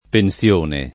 pensione [ pen SL1 ne ] s. f.